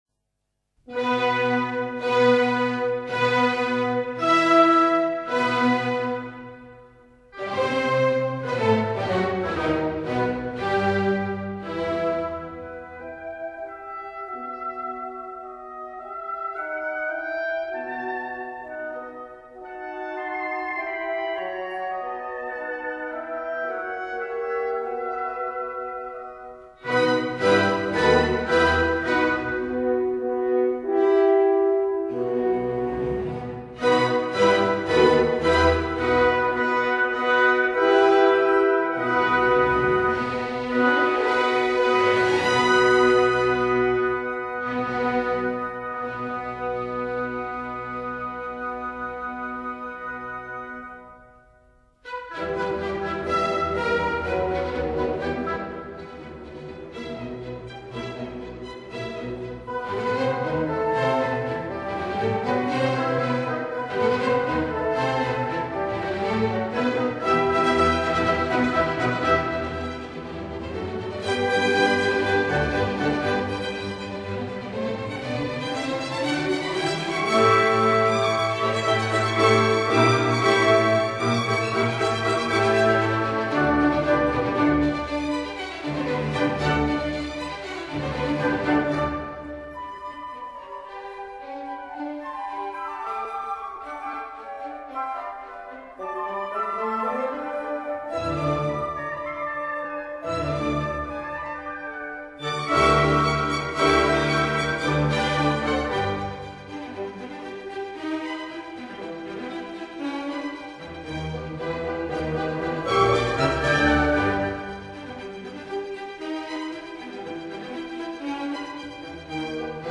韋瑟是丹麥古典主義時期最重要的作曲家，他的音樂風格類似維也納古典樂派，具有和諧，優美，雅致的特點。